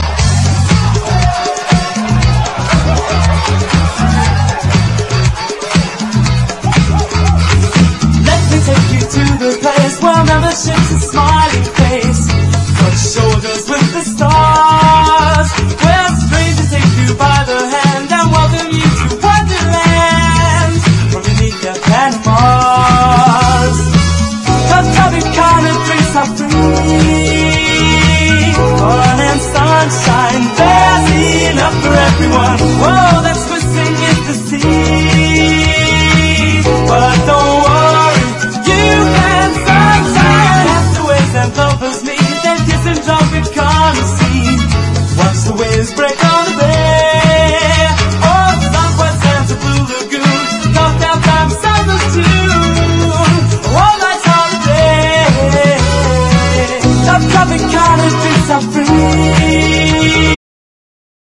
¥1,680 (税込) ROCK / 80'S/NEW WAVE.